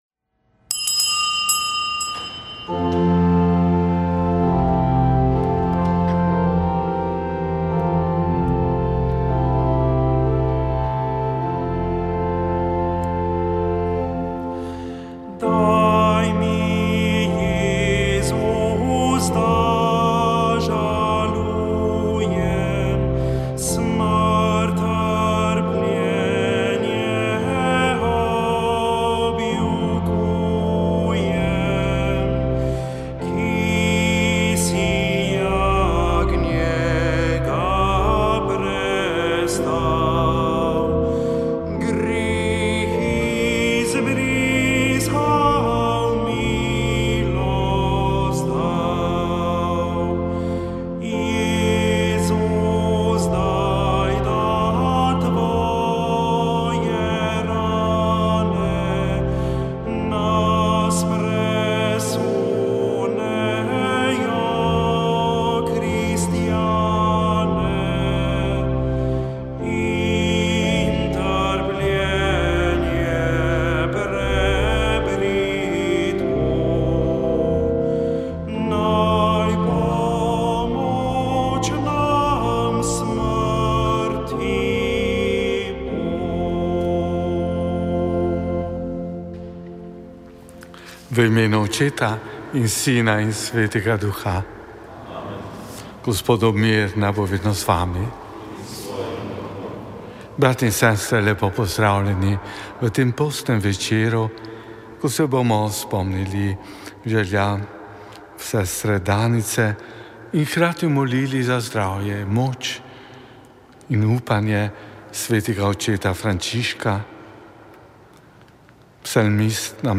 Sveta maša
Sv. maša iz stolnice svetega Nikolaja v Ljubljani 6. 5.